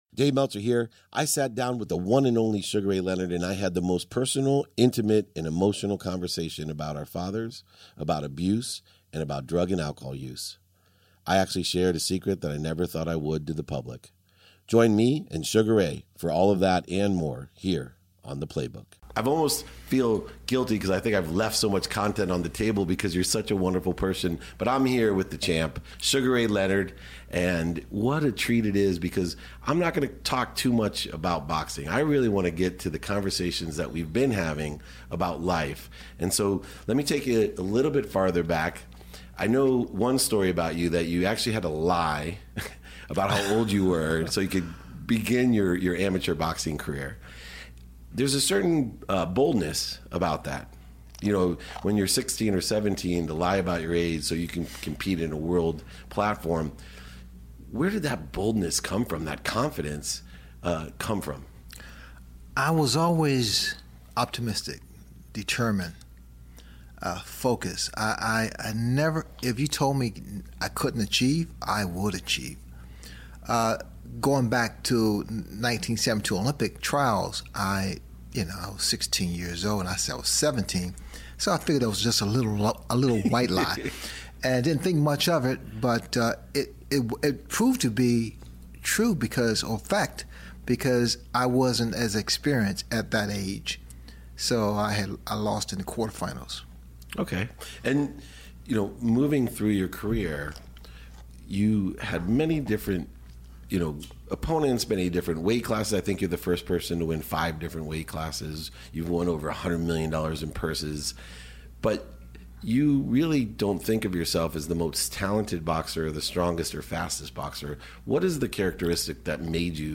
We share a profoundly personal conversation, reflecting on our fathers, overcoming adversities, and the role of resilience in our lives. Sugar opens up about his early challenges, the drive behind his historic career spanning five weight classes, and his journey beyond the ring.